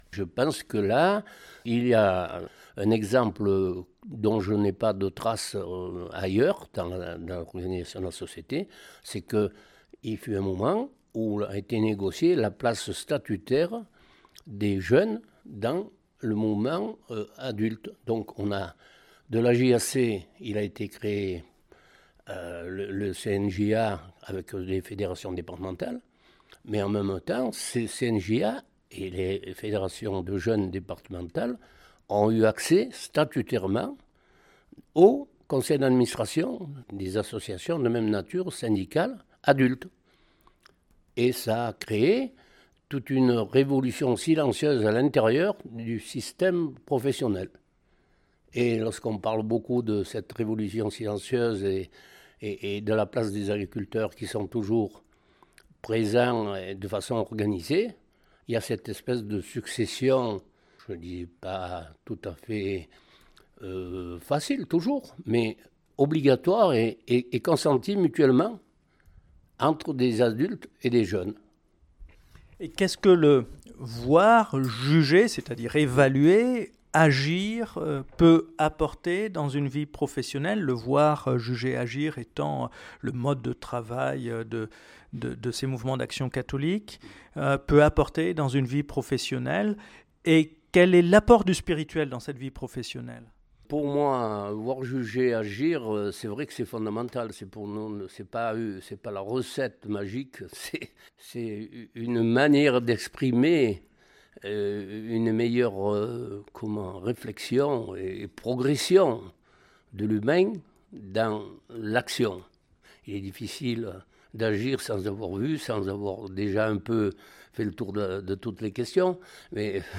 Dans l’interview ci-dessous